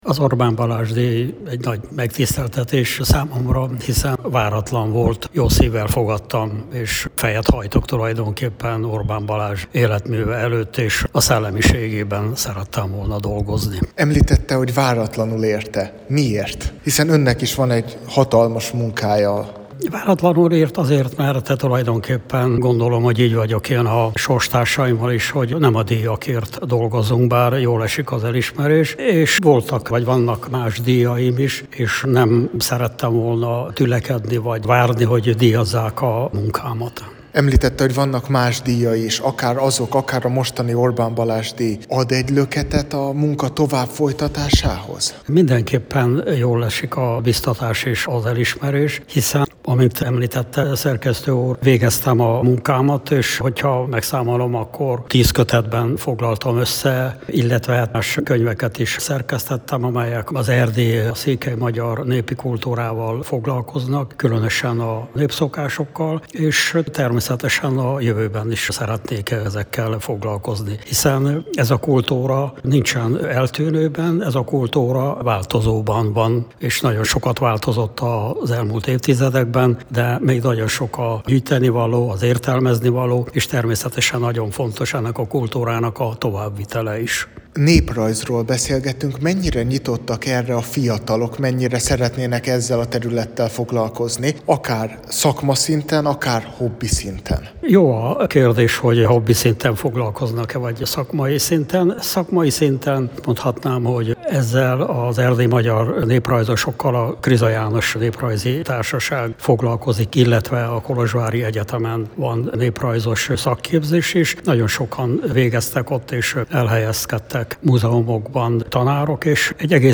Az ünnepélyes díjátadóra október 1-én került sor Marosvásárhelyen, a Kultúrpalotában.